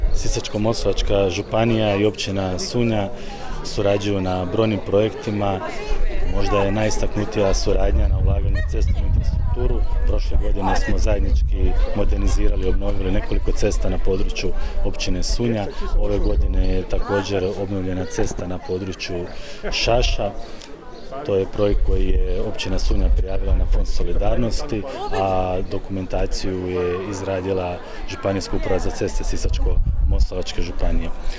U Sunji je održana svečana sjednica Općinskog vijeća u prigodi obilježavanja Dana Općine Sunja  i blagdana zaštitnice sv. Marije Magdalene, te XXVII. Državna konjogojska izložba.
Dobru suradnju s Općinom potvrdio je i župan Ivan Celjak